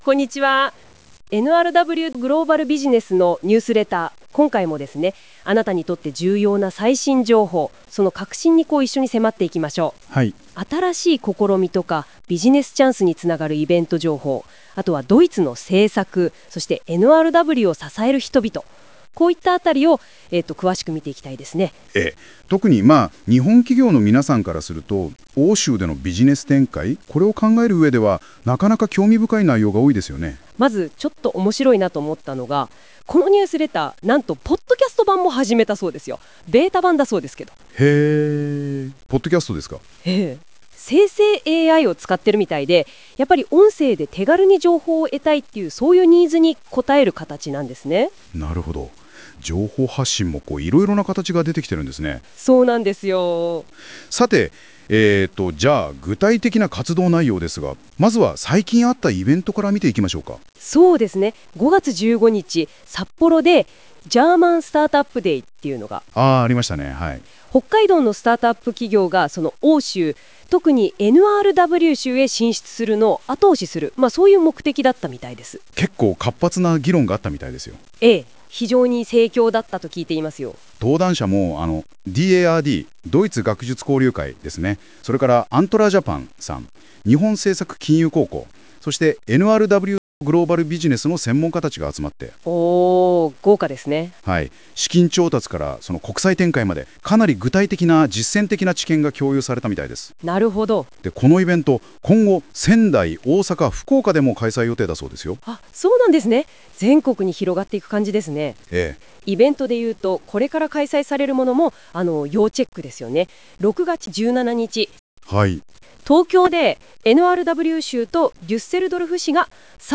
生成AIを使用しているため、ベータ版という扱いだが、NRW州や私たちのニュースを音声でご提供できる新しい機会となる。